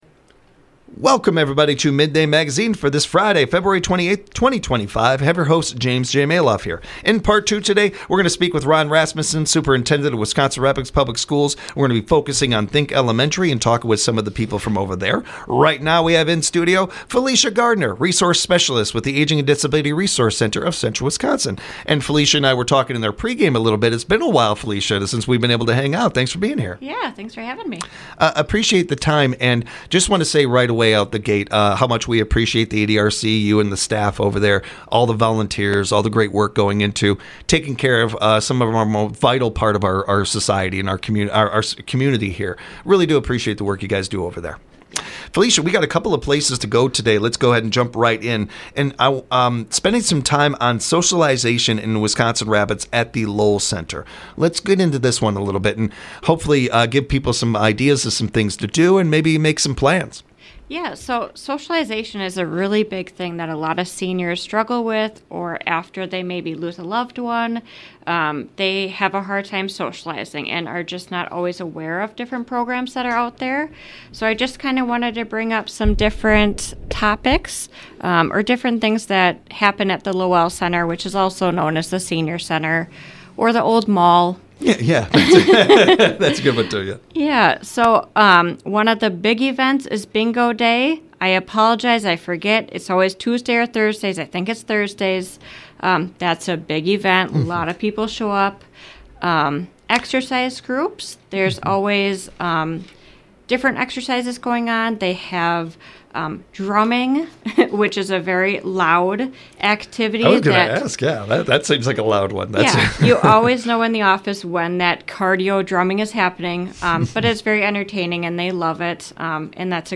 We're building a state-wide radio network that broadcasts local news